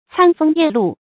餐風咽露 注音： ㄘㄢ ㄈㄥ ㄧㄢˋ ㄌㄨˋ 讀音讀法： 意思解釋： 形容旅途或野外生活的艱苦。